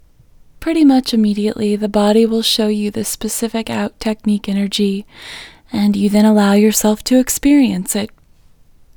LOCATE OUT English Female 35